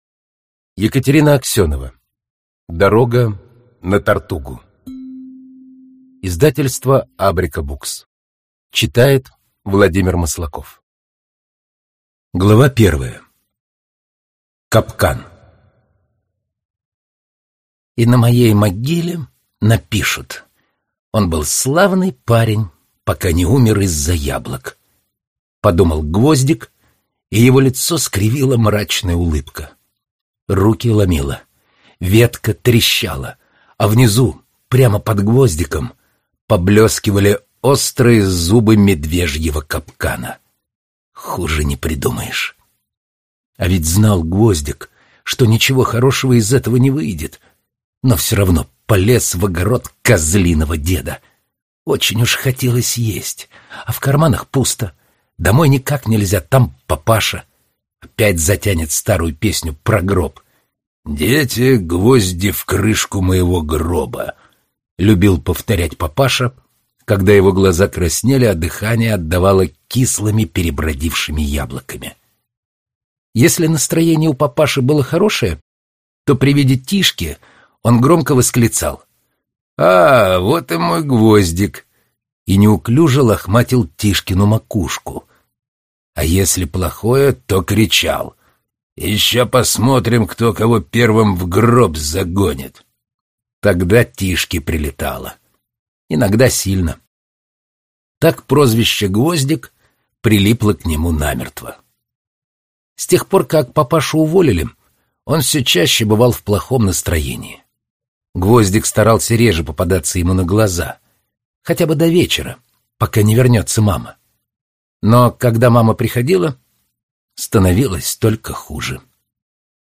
Аудиокнига Дорога на Тортугу | Библиотека аудиокниг